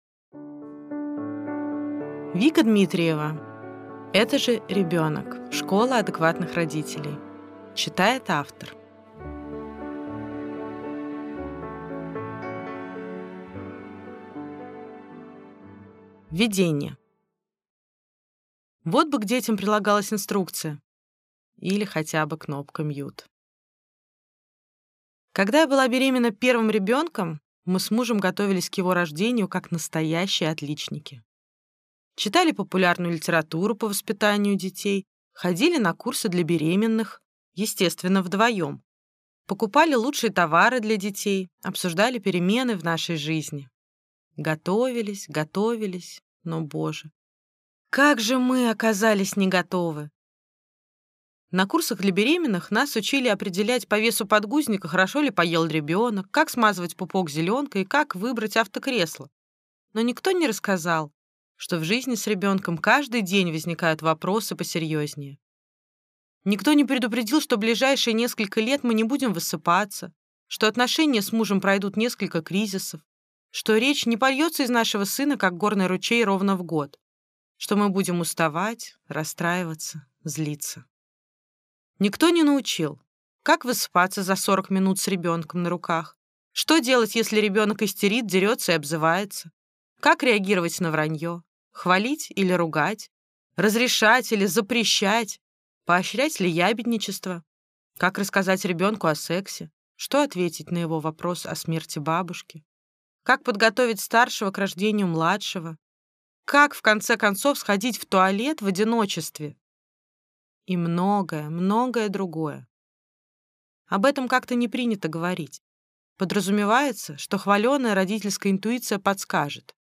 Аудиокнига Это же ребенок!